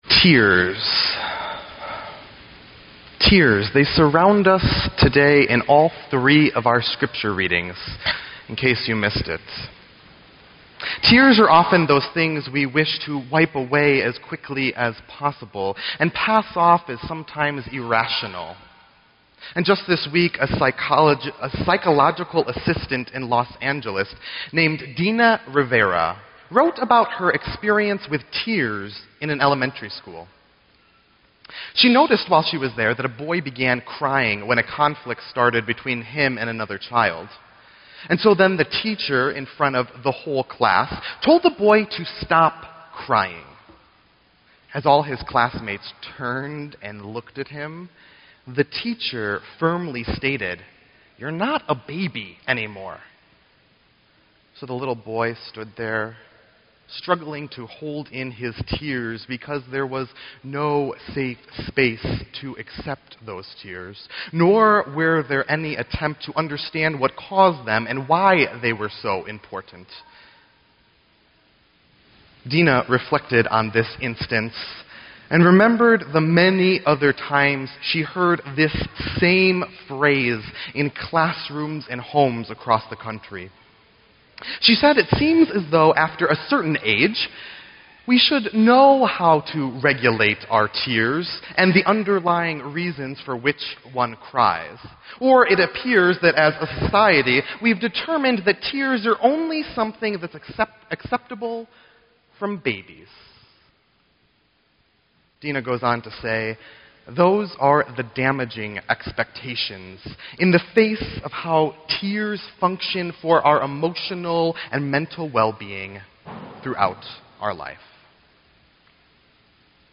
Sermon_11_1_15.mp3